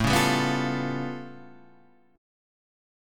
A+9 Chord
Listen to A+9 strummed